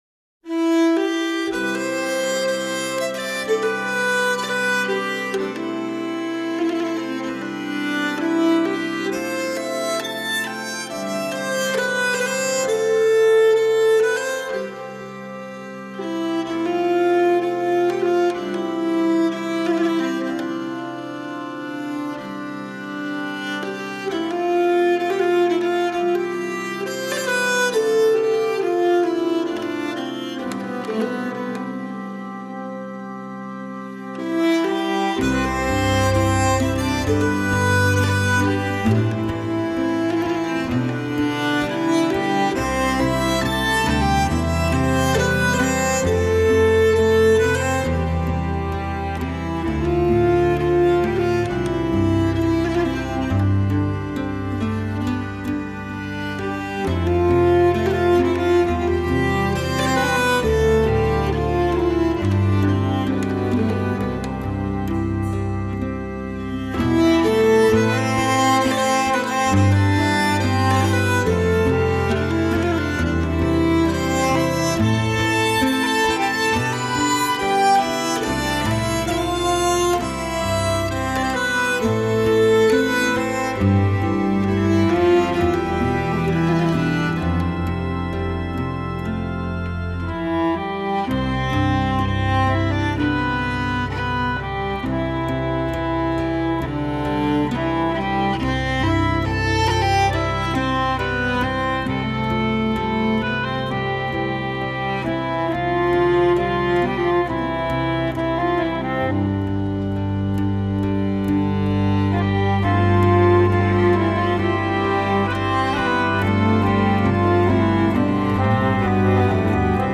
傲世的挪威民谣搭配风笛、曼陀林、鼓、管风琴等乐器